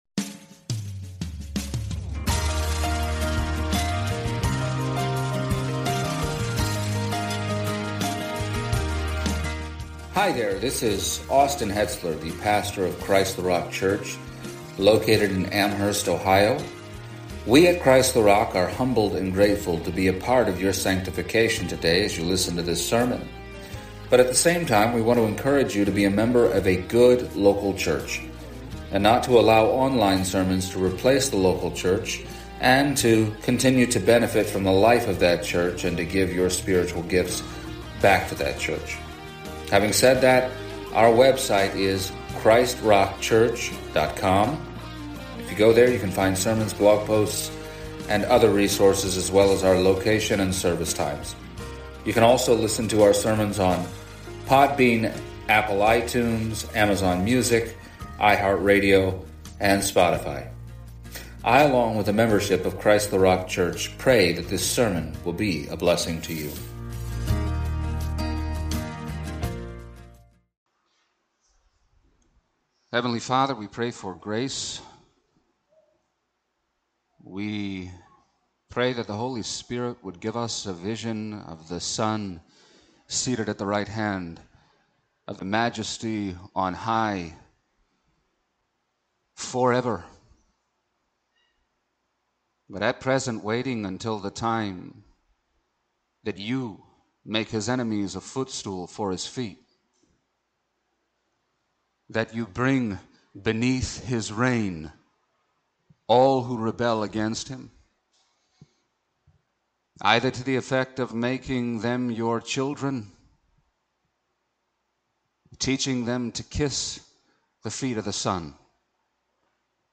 Passage: Hebrews 1:13-14 Service Type: Sunday Morning